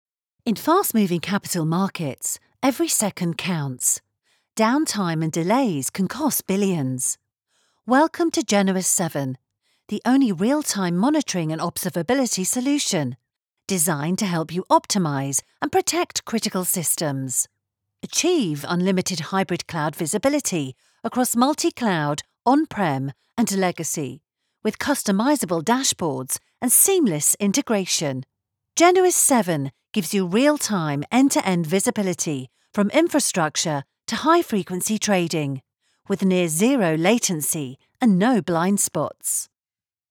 With my clear, bright British tones, I bring a touch of class to every project, ensuring your message resonates with listeners everywhere.
Words that describe my voice are British, Friendly, Natural.